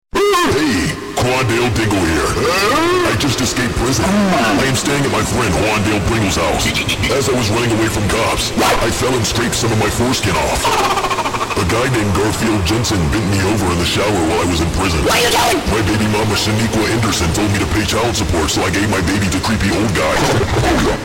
goofy dad sound effect download for free mp3 soundboard online meme instant buttons online download for free mp3